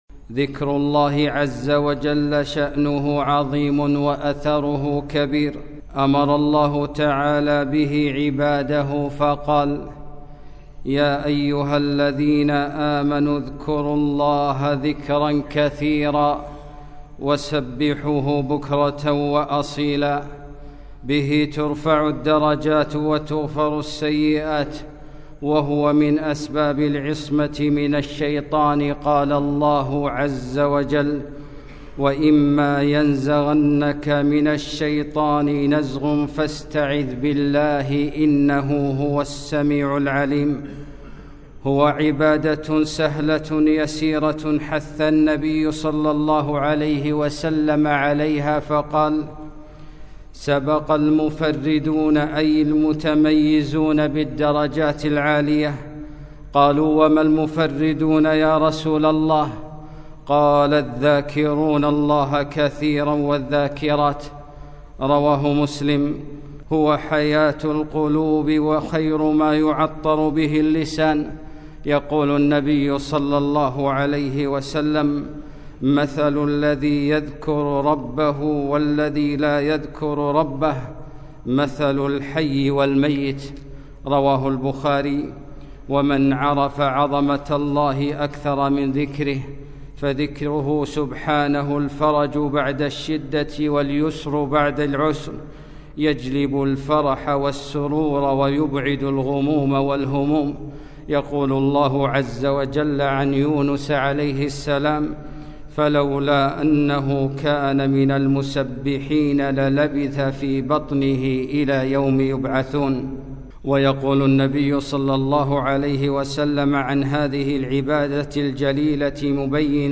خطبة - سبق المفردون